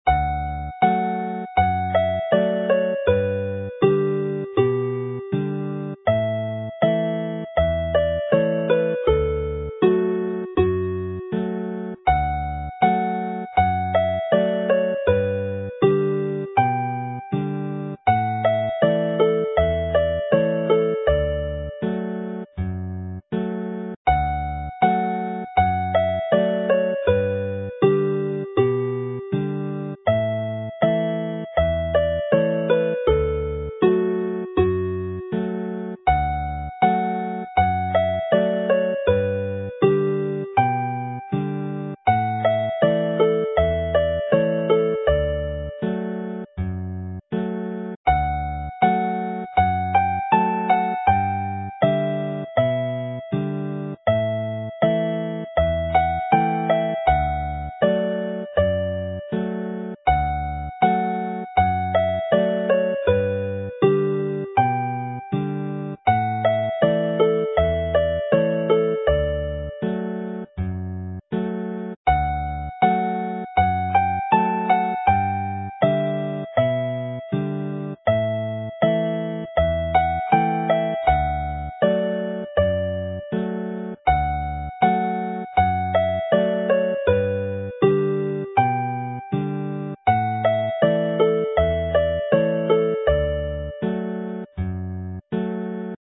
Joio is the South Walian slang Welsh derived from the English enjoy and is the jolliest tune in the set.
Play slowly